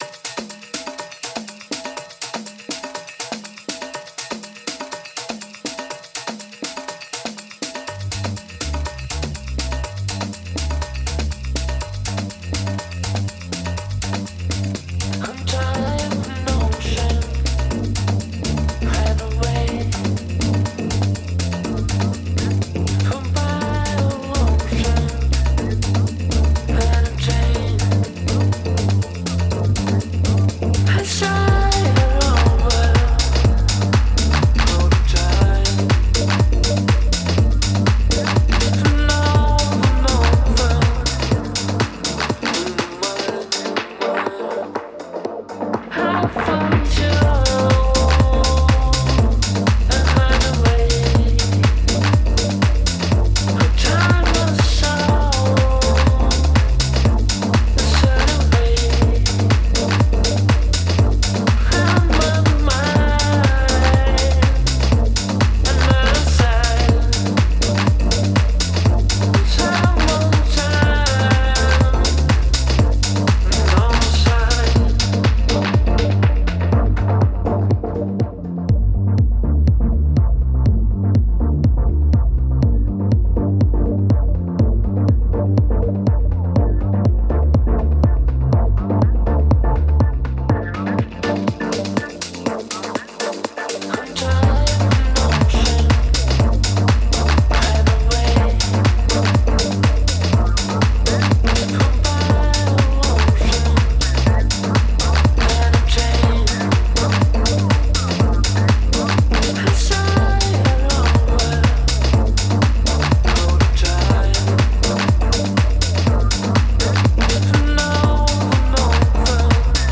house, afro
Тембр голоса кайфный..) Только внизу грязновато, и немного в нижней середине..